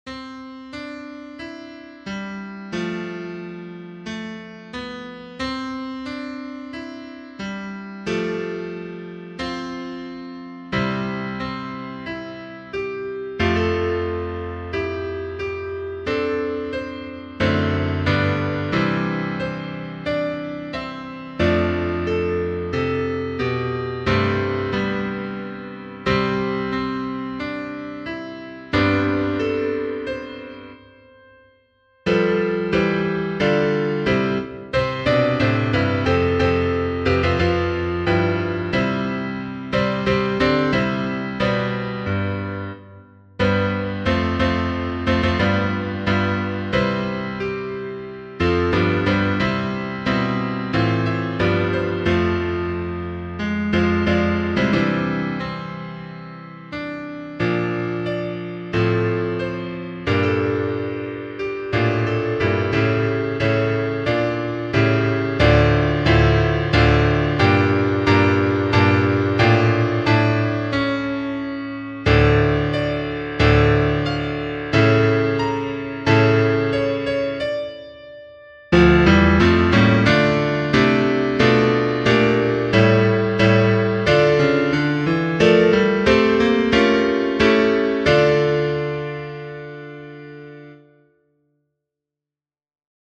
I decided I wanted to enter a contest for writing choral music.